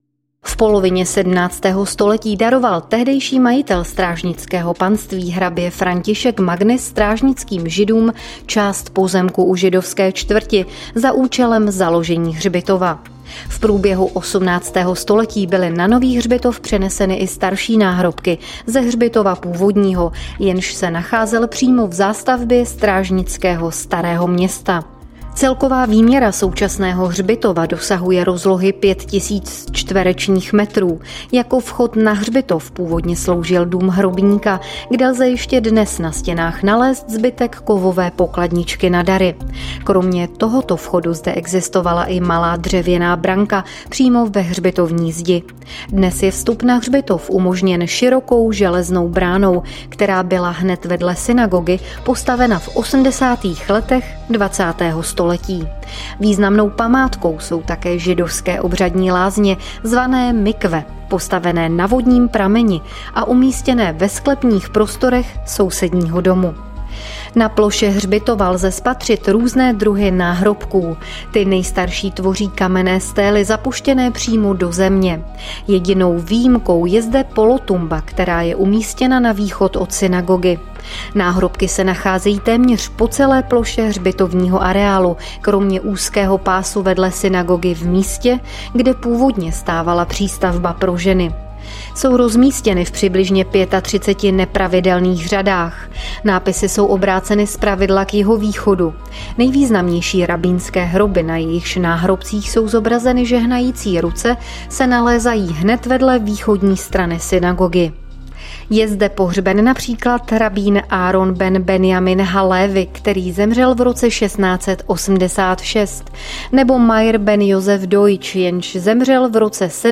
Zvukový průvodce